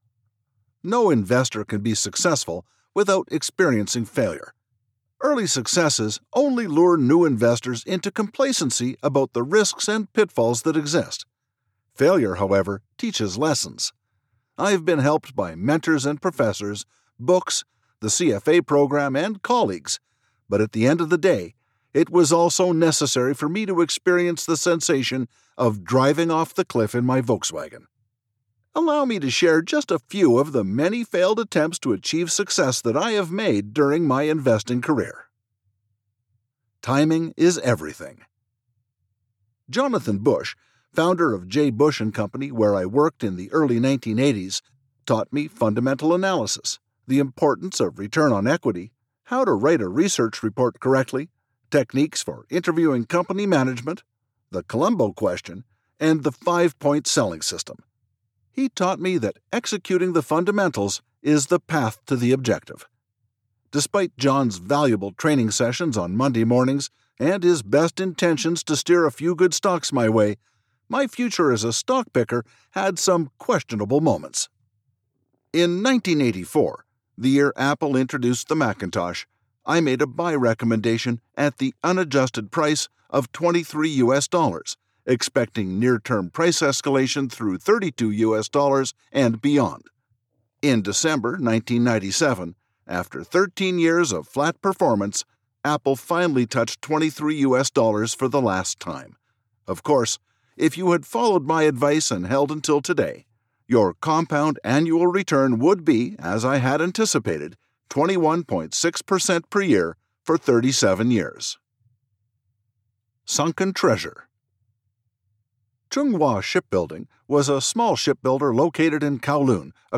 non-fiction business audiobook
1st person non-fiction business.mp3